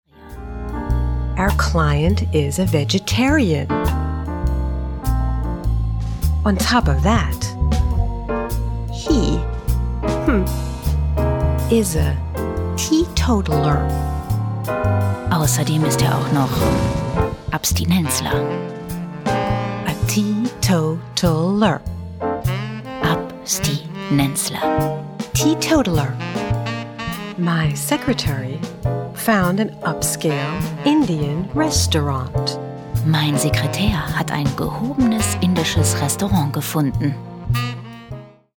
englische Sprecherin, Muttersprache: Englisch (USA) und verschiedene British / Amerikanische Akkzente,
mid-atlantic
Sprechprobe: Industrie (Muttersprache):
english voice over artist (us)